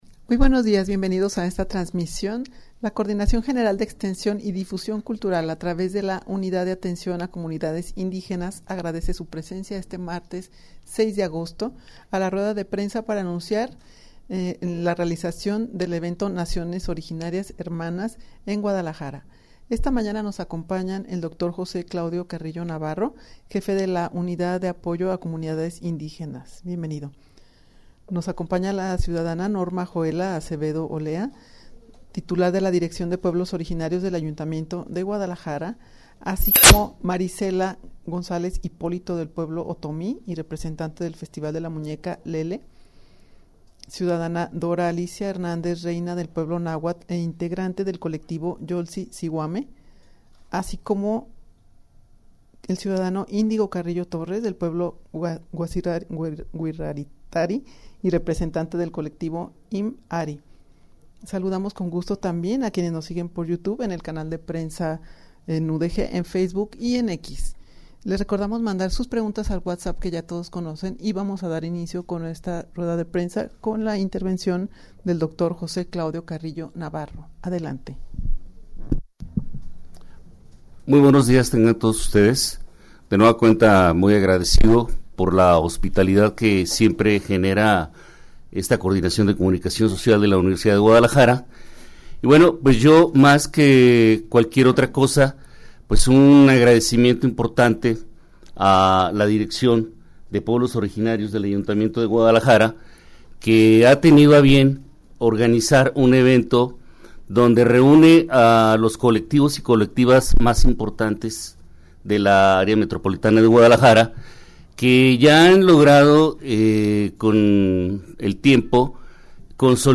rueda-de-prensa-en-la-que-se-anunciara-la-realizacion-del-evento-naciones-originarias-hermanas-en-guadalajara.mp3